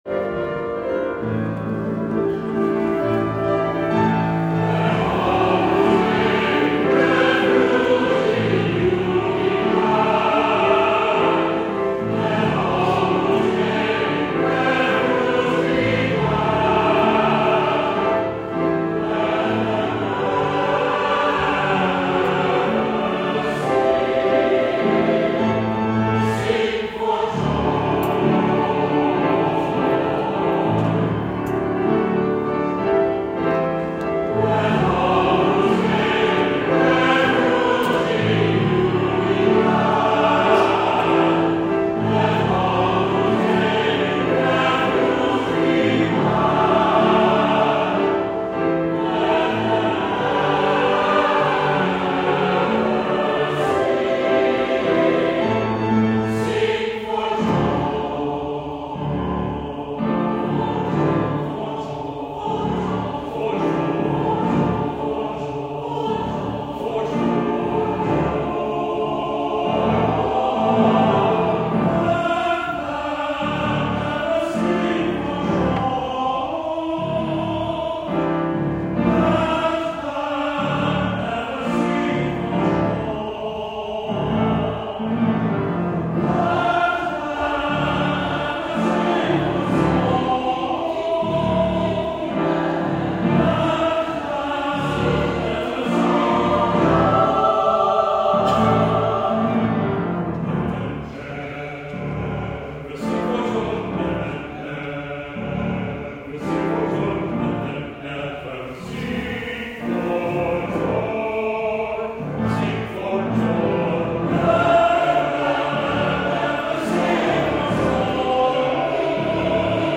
for SATB div. choir and piano
is a sacred concert piece bursting with energy and hope.